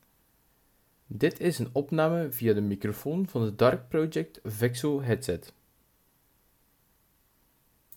De microfoon die zich op de Dark Project Vexo-headset bevindt, is van gemiddelde kwaliteit. Om een duidelijker inzicht te krijgen in de kwaliteit van deze microfoon, kan je gerust eens luisteren naar het audiofragment.